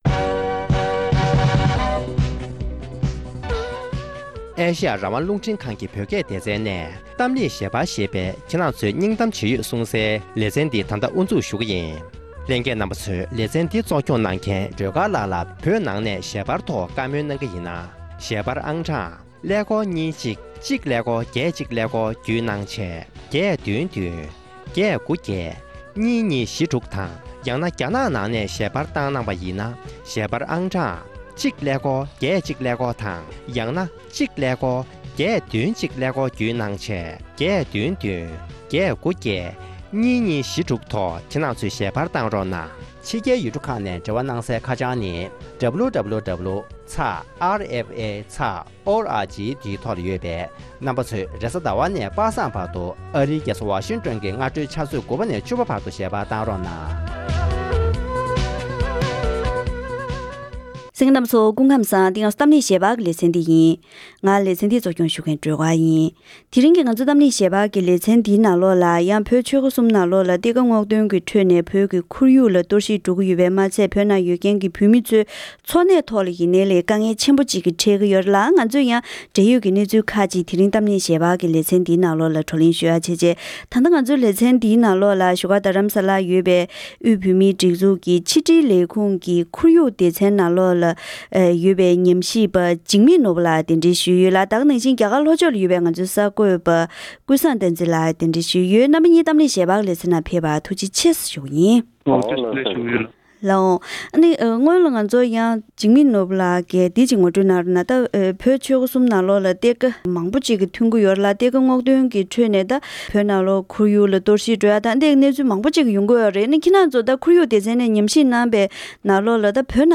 འབྲེལ་ཡོད་མི་སྣ་དང་བགྲོ་གླེང་ཞུས་པ་ཞིག་གསན་རོགས་ཞུ།།